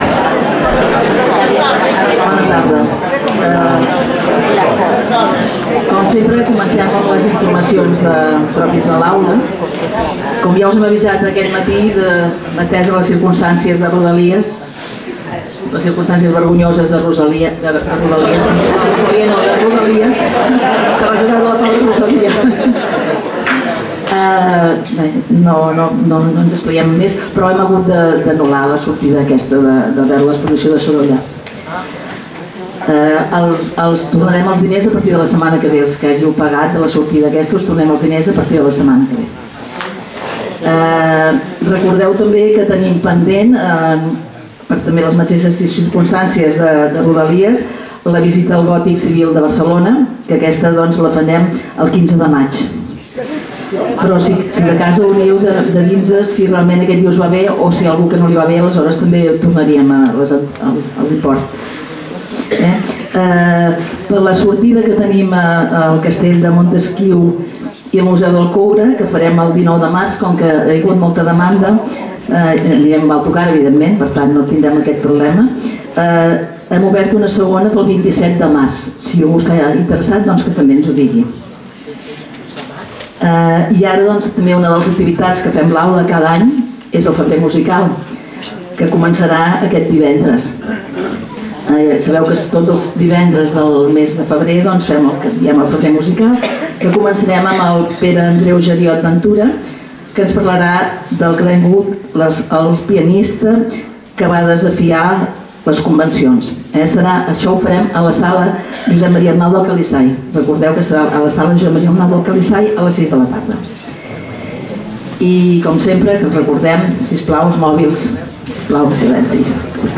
Lloc: Casal de Joventut Seràfica
Categoria: Conferències